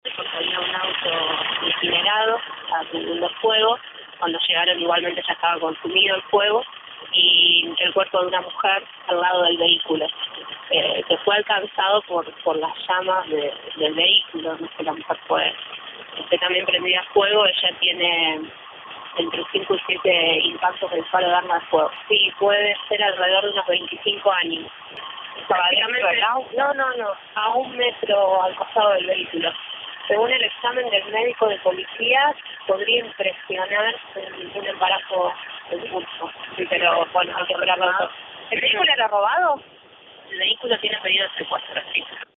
La fiscal Paolicelli dio detalles del crimen de la mujer asesinada e incinerada